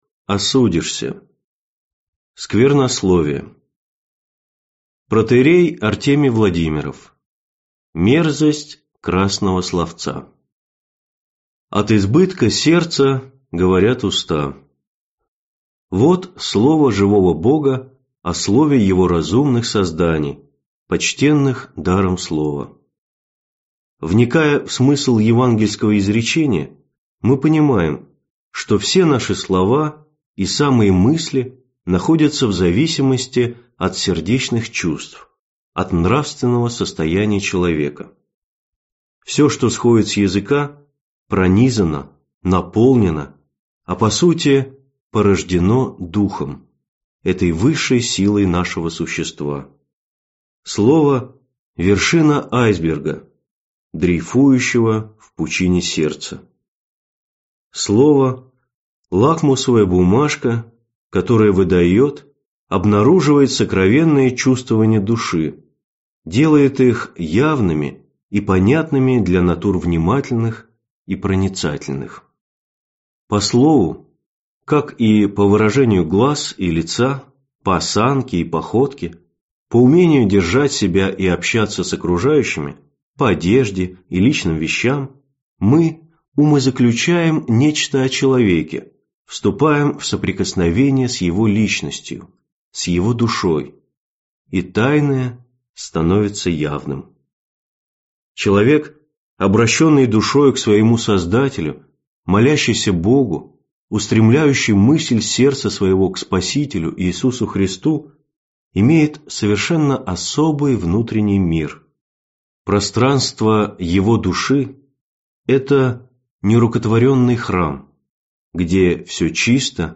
Аудиокнига От слов своих осудишься: сквернословие | Библиотека аудиокниг